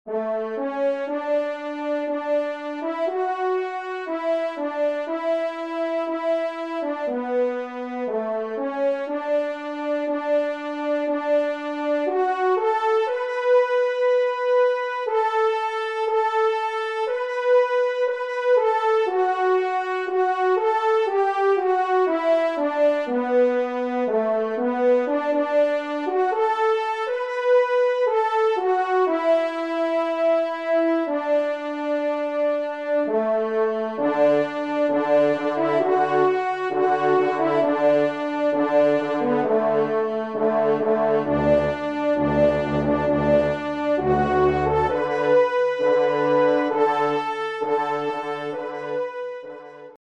Auteur : Chant Traditionnel Écossais
3e Trompe